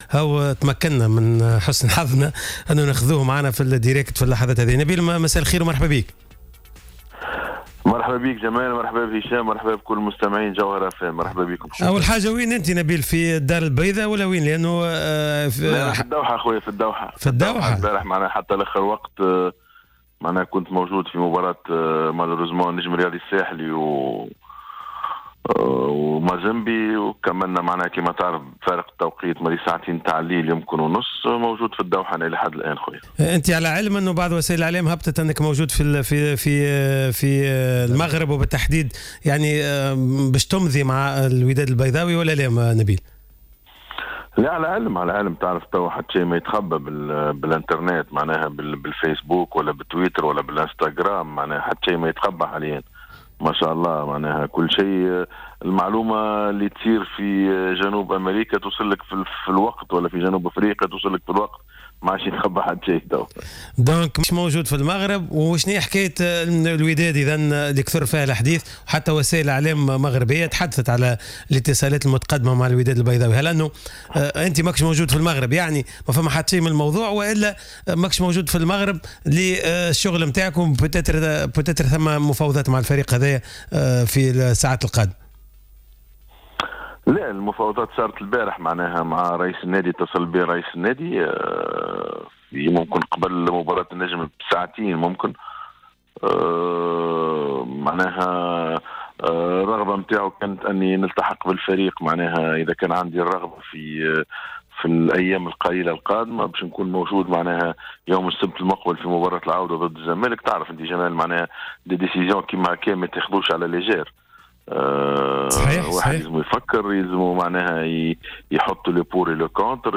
أكد نبيل معلول في مداخلة في برنامج "راديو سبور" أنه لم يتحول إلى المغرب لإنهاء المفاوضات مع الوداد البيضاوي كما وقع تداوله معربا أنه قد أغلق ملف تدريب الفريق رغم أن العرض المغري الذي تلقاه من هيئة الفريق.